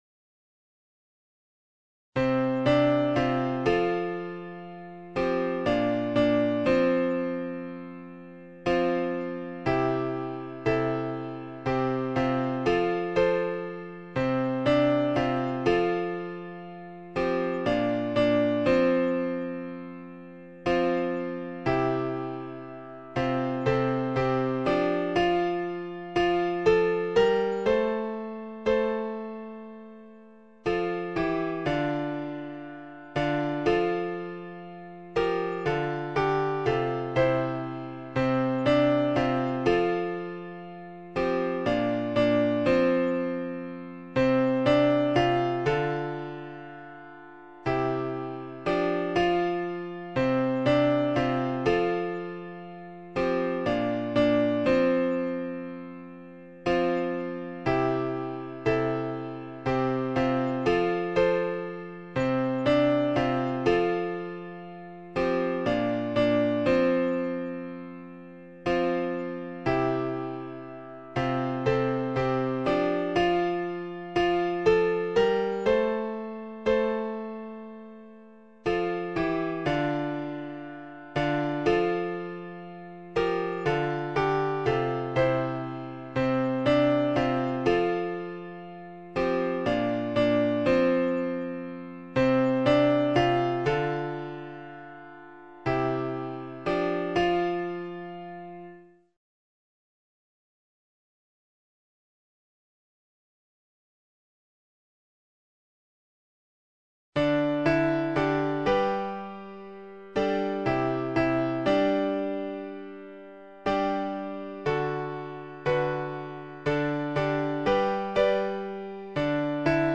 SAB